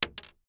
Bullet Shell Sounds
pistol_wood_1.ogg